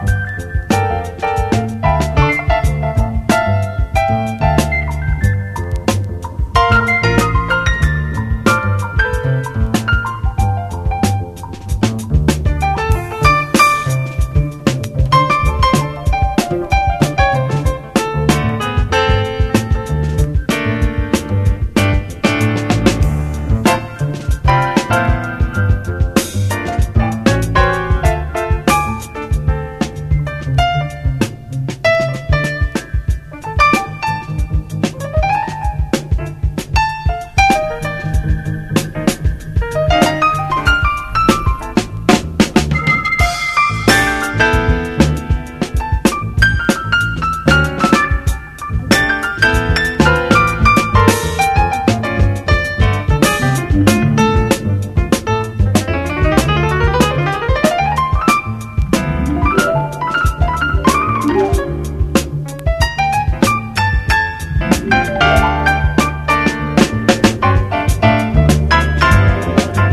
ROCK / 60'S / EASY LISTENING / MOOG / PSYCHEDELIC